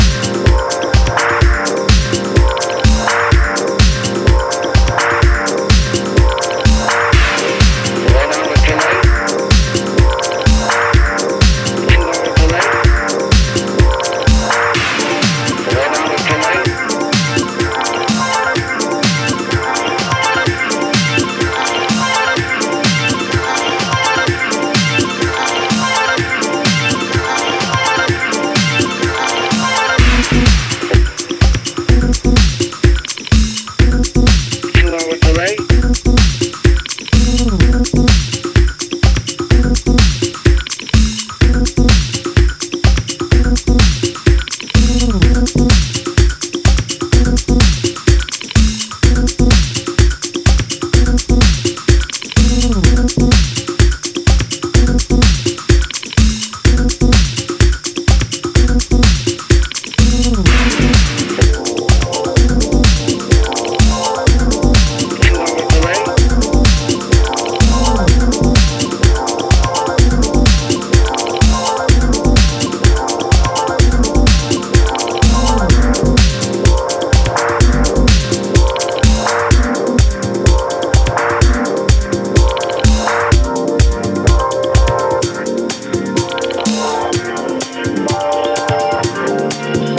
音質も良好。
ジャンル(スタイル) DEEP HOUSE / NU DISCO